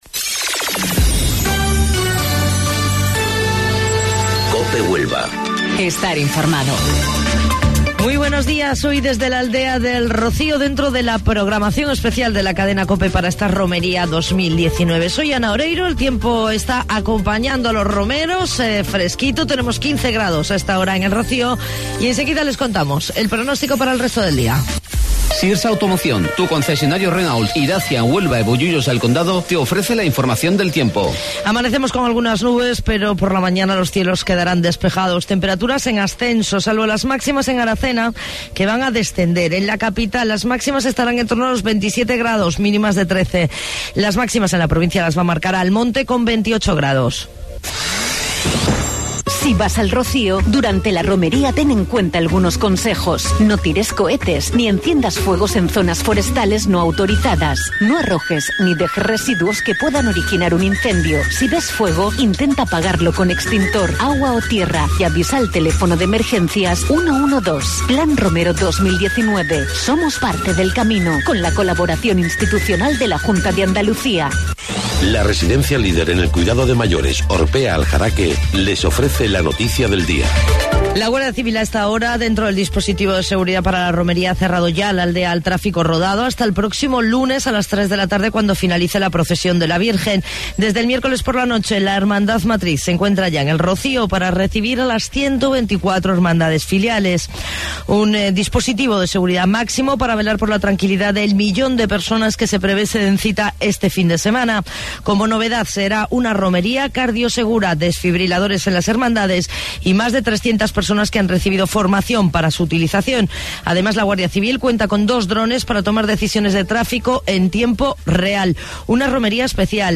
AUDIO: Informativo Local 08:25 del 7 de Junio